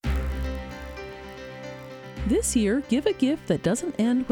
Problem with noise at beginning of Export using Envelope
The issue only happens when you use the Envelope tool to do a fade down into the voice over and export it to a file.
This is a very curious but distinctive type of distortion.
In your WAV file there are clicks at regular intervals of exactly 792 samples.